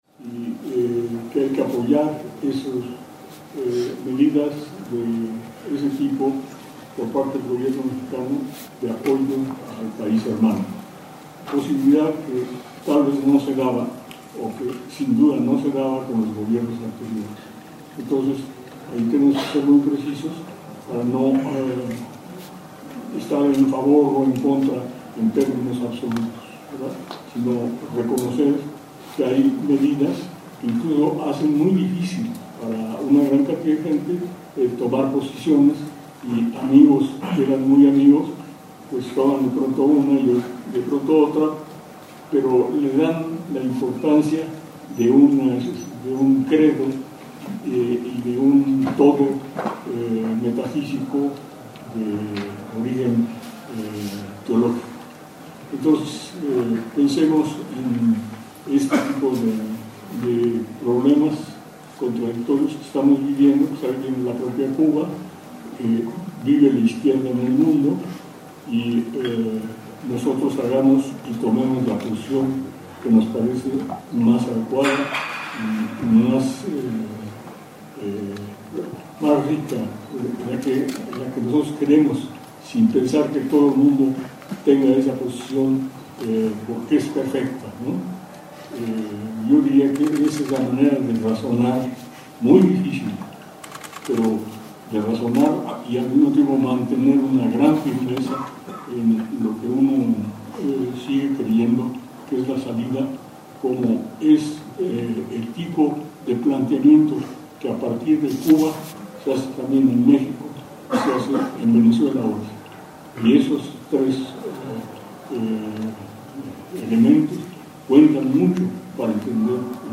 Tenemos que ser muy precisos para no estar en favor o en contra en términos absolutos, sino reconocer que hay medidas que incluso hacen muy difícil para una gran cantidad de gente el tomar posiciones: amigos que eran muy amigos toman de pronto una [posición] y yo tomo otra, pero le dan la importancia de un credo y de un todo metafísico de origen teológico”, dijo al participar en la conferencia magistral del embajador de Cuba en México, Pedro Núñez Mosquera, sobre la Ley Helms Burton, celebrada en la Facultad de Ciencias Políticas y Sociales de la UNAM.